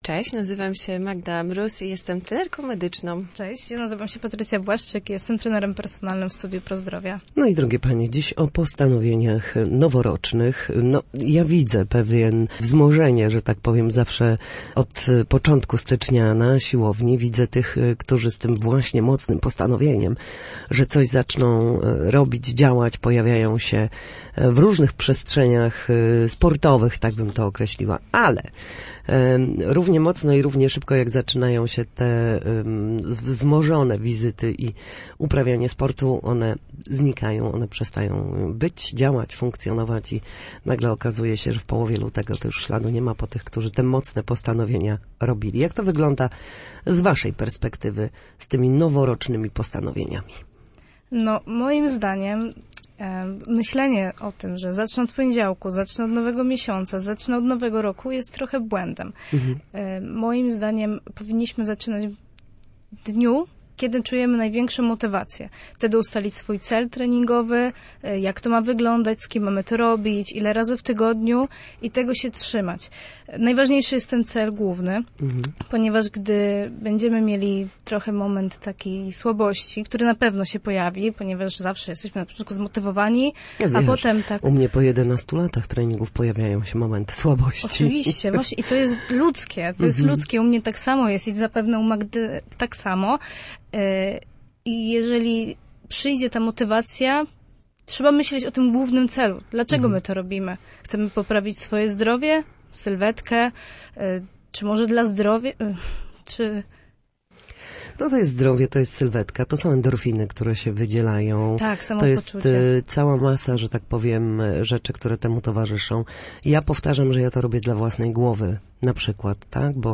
W każdą środę, w popołudniowym Studiu Słupsk Radia Gdańsk, rozmawiamy o tym, jak wrócić do formy po chorobach i urazach.